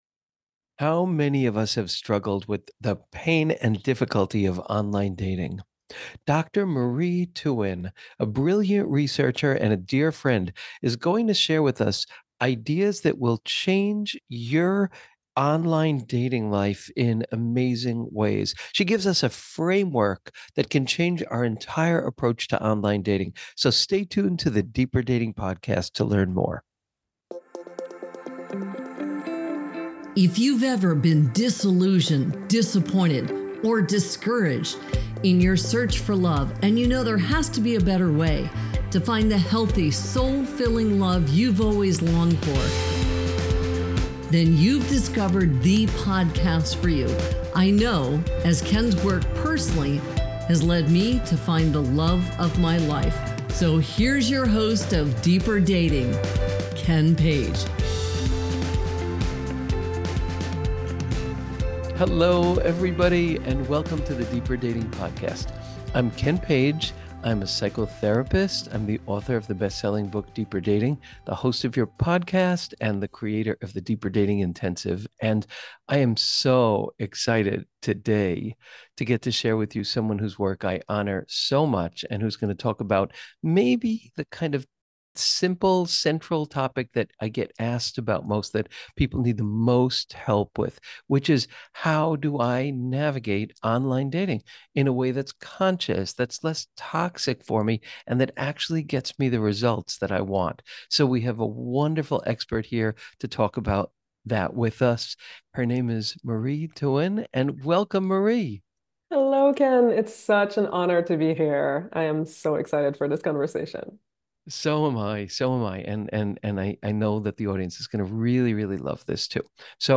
4 Keys to Make Online Dating Work: Interview